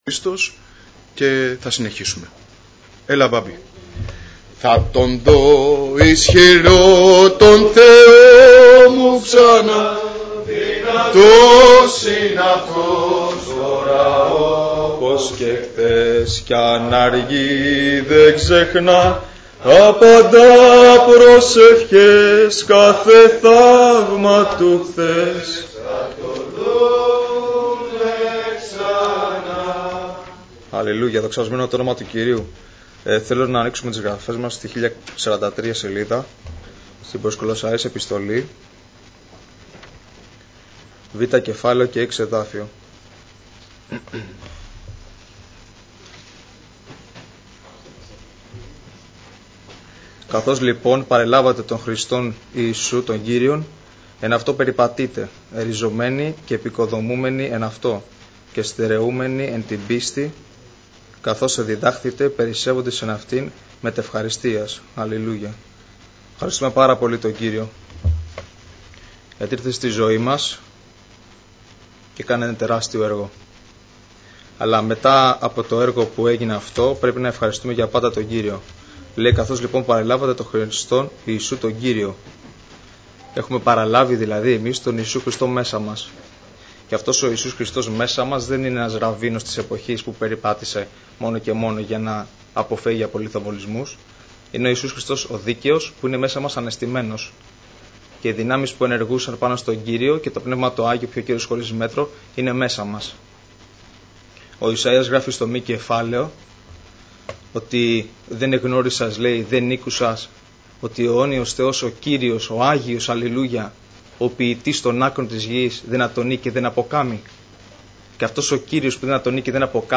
Νεολαία Αγίας Παρασκευής Ομιλητής: Νεολαία Αγίας Παρασκευής Λεπτομέρειες Σειρά: Κηρύγματα Ημερομηνία: Κυριακή, 02 Νοεμβρίου 2014 Εμφανίσεις: 521 Γραφή: Προς Κολοσσαείς 2:6-2:7 Λήψη ήχου Λήψη βίντεο